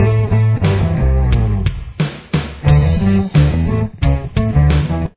The Main Riff